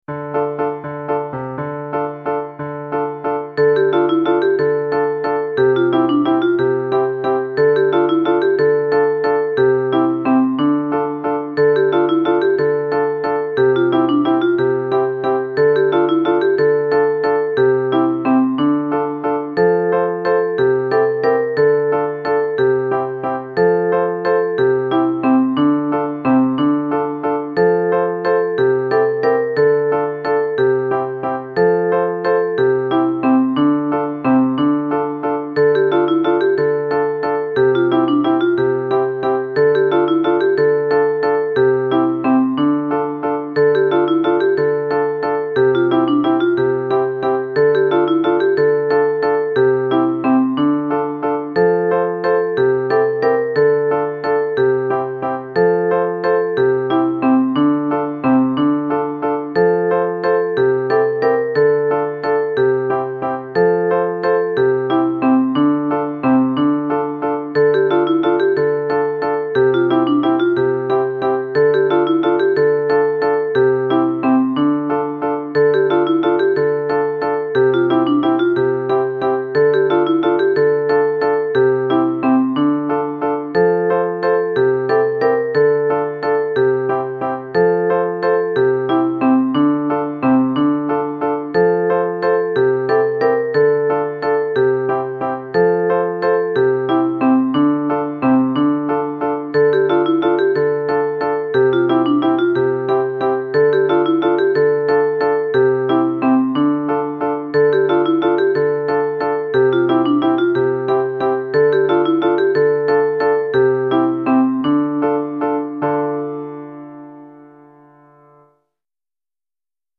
Chorale d'Enfants et Piano ou Guitare